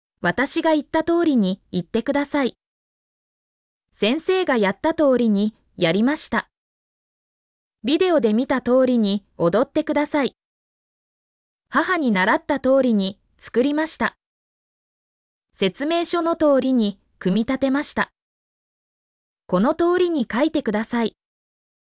mp3-output-ttsfreedotcom-11_zFUyhBus.mp3